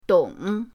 dong3.mp3